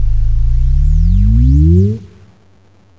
ClockAura.wav